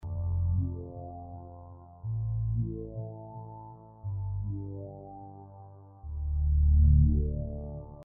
标签： 120 bpm Electronic Loops Pad Loops 1.35 MB wav Key : Unknown
声道立体声